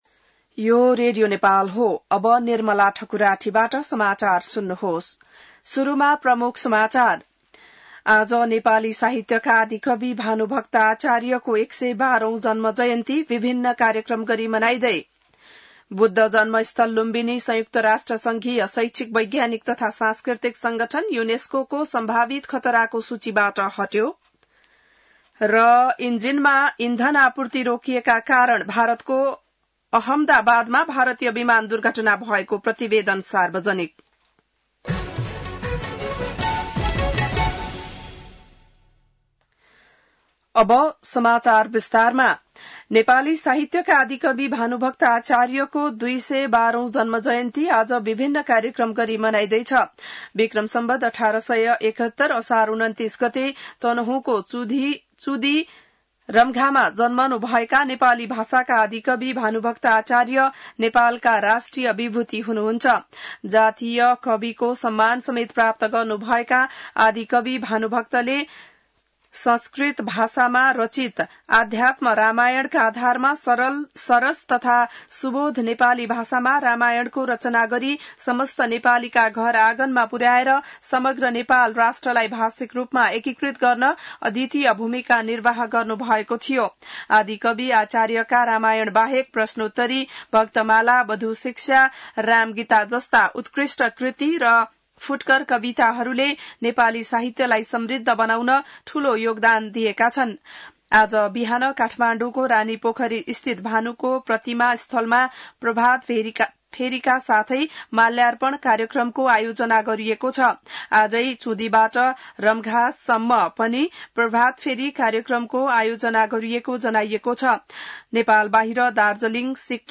बिहान ९ बजेको नेपाली समाचार : २९ असार , २०८२